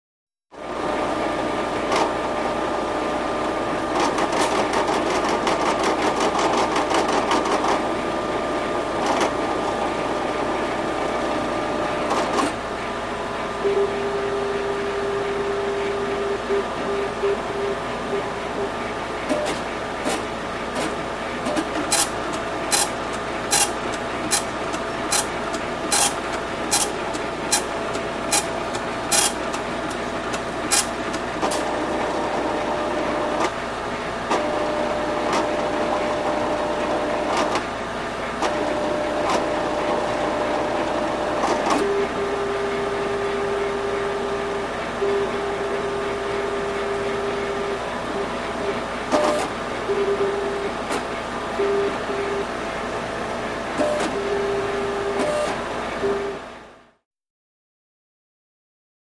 Tdx Drug Machine; Tdx Drug Machine; Motors / Fans In Background, Mechanical Clicks / Beeps / Printing In Foreground, Close Perspective. Hospital, Lab.